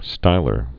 (stīlər, -lär)